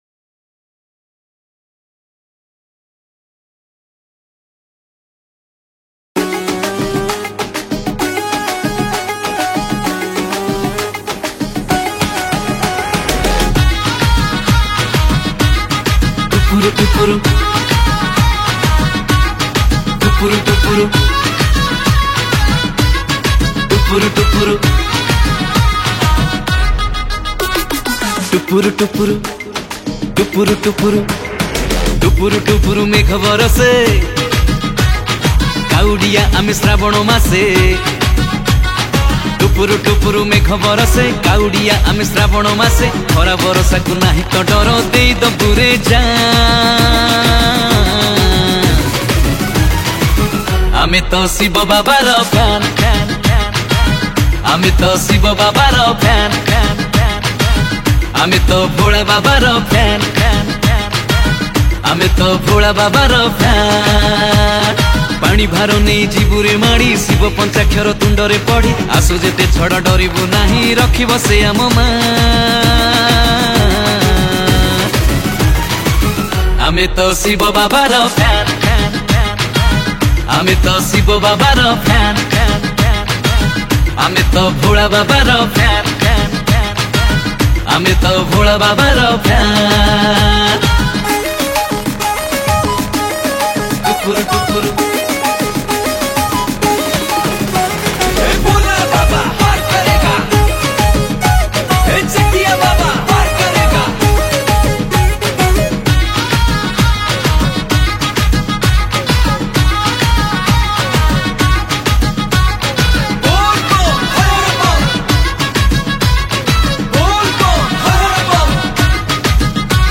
Kaudia Bhajan
Bolbum Special Song